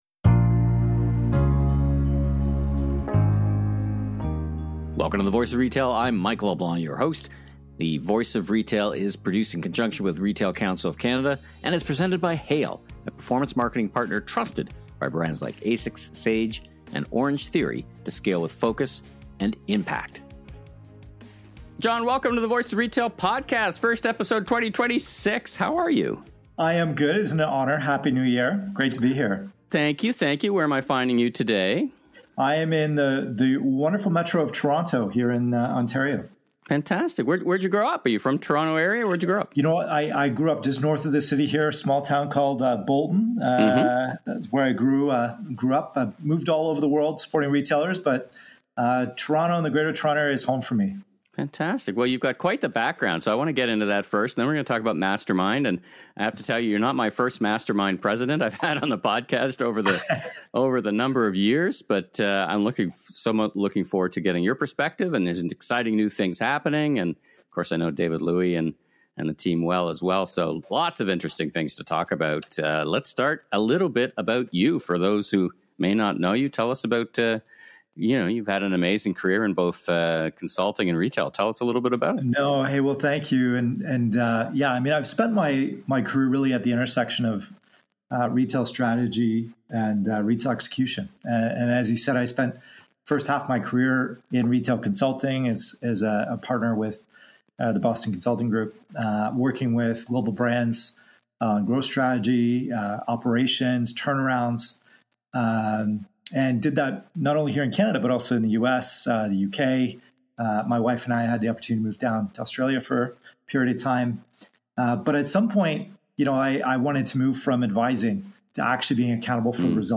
Each and every week I interview the most interesting people in and around the retail industry, examining the key issues and strategies on the minds of thought leaders in Canada, the U.S. and around the world.